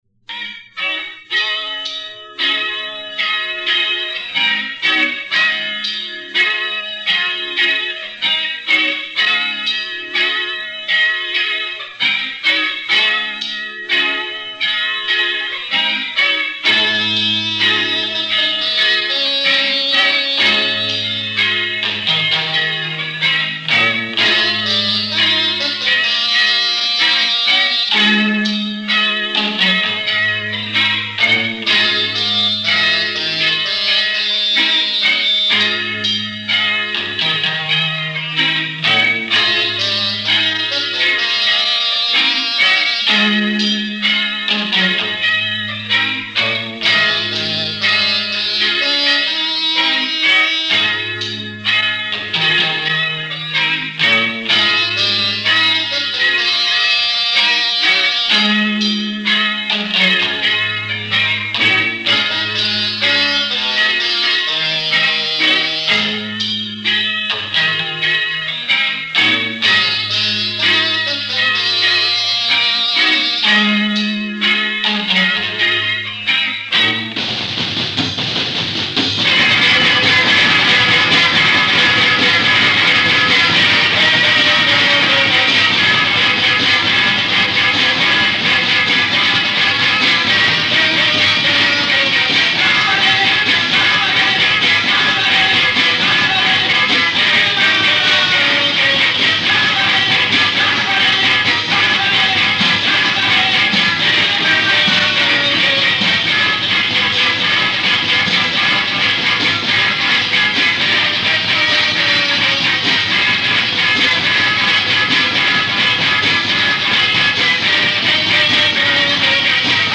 je česká avantgardní rocková kapela.